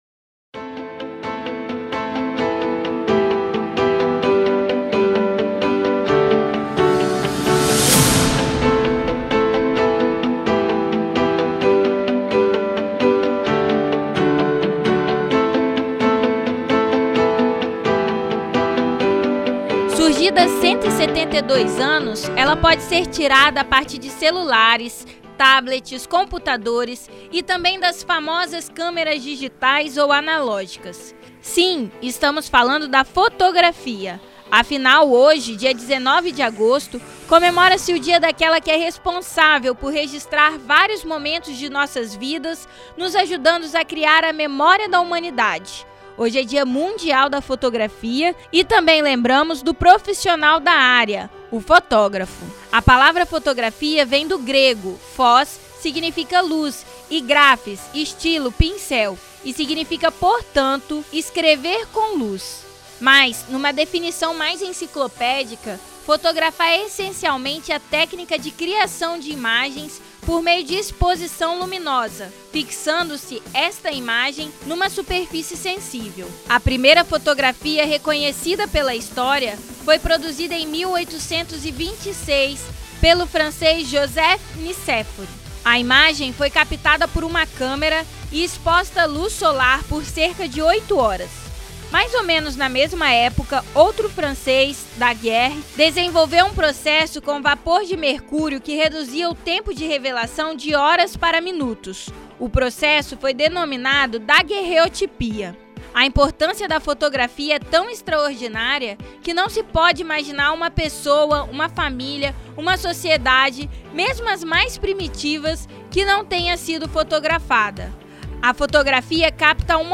Confira a matéria que foi ao ar no programa Revista Universitária.